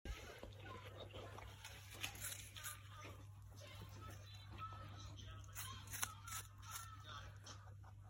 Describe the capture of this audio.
Sorry about the sound in background